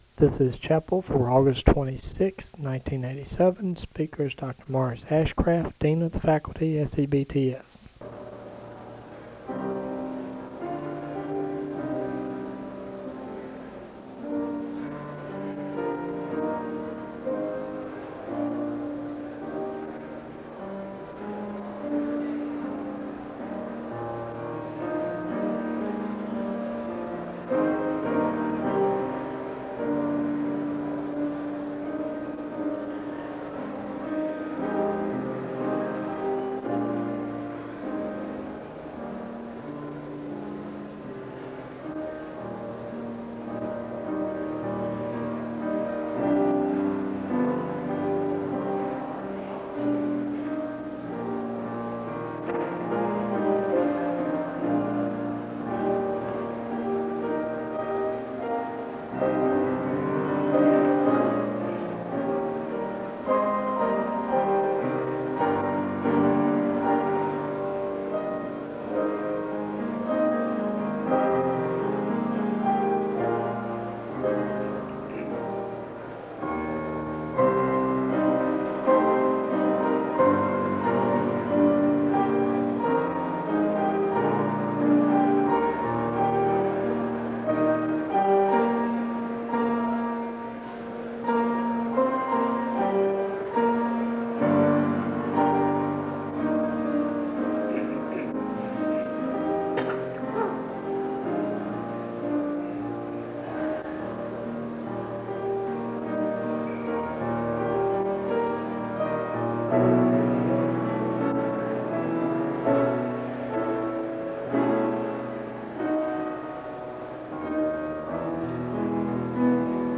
The service begins with piano music (0:00-3:01). There is a Scripture reading (3:02-3:40).
There is a Scripture reading from II Corinthians 4 and a moment of prayer (6:31-9:32). The choir sings a song of worship (9:33-12:24).